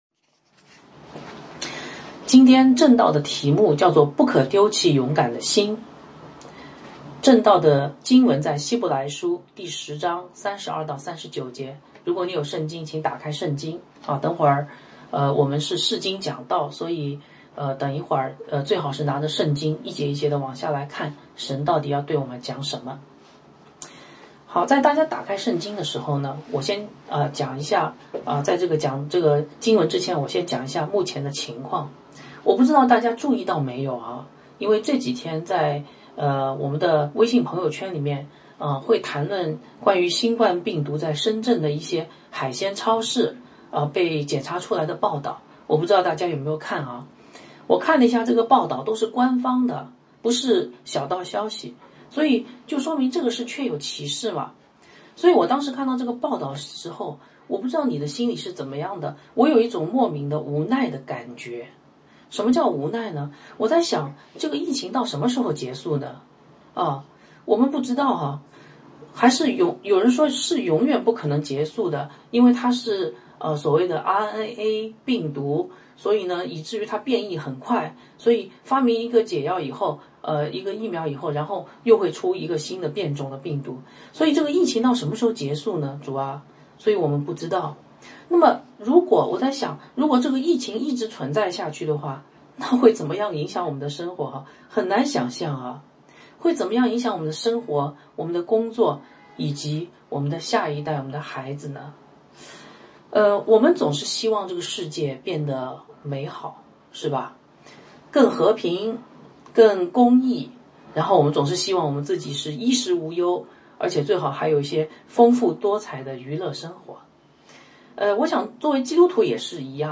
讲道